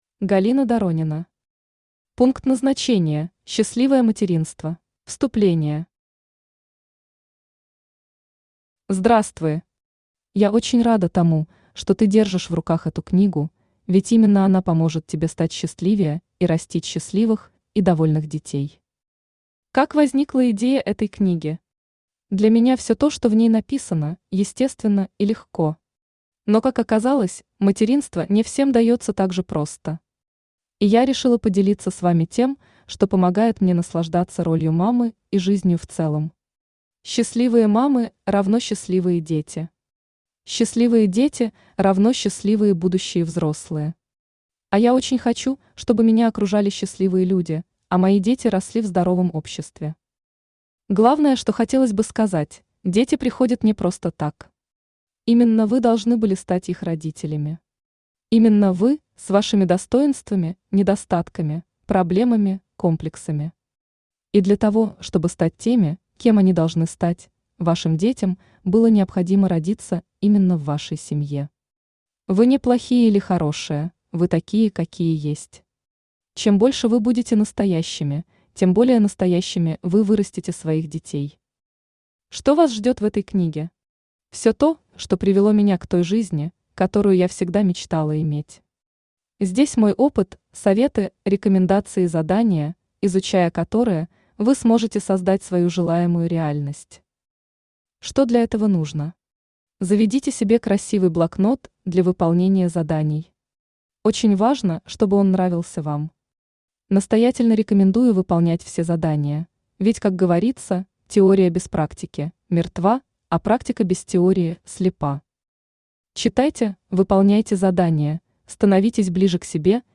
Читает: Авточтец ЛитРес
Аудиокнига «Пункт назначения: счастливое материнство».